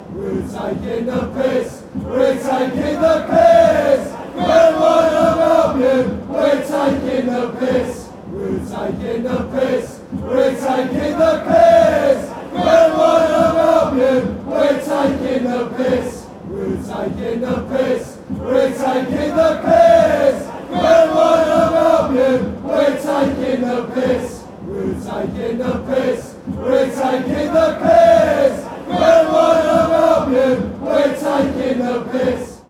A BHAFC soccer chant.